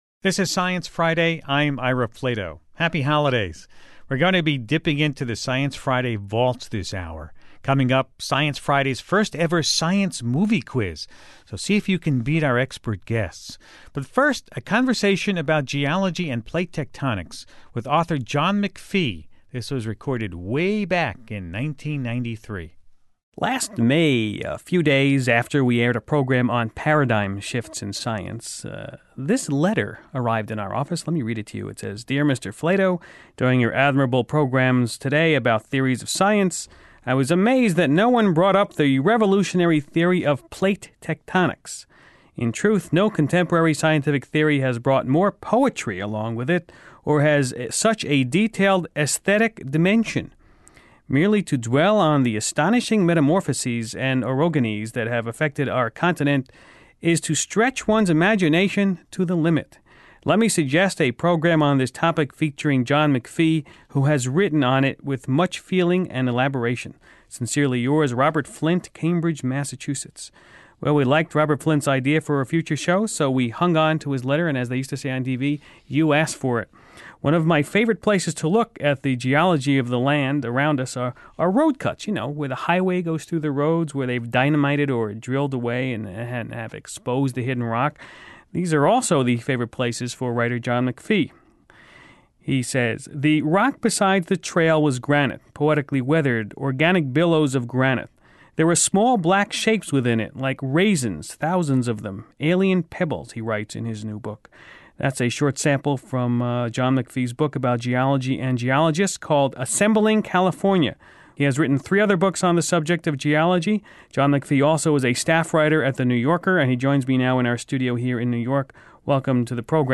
In this 1993 interview from the Science Friday archives, writer John McPhee talks plate tectonics and global geology.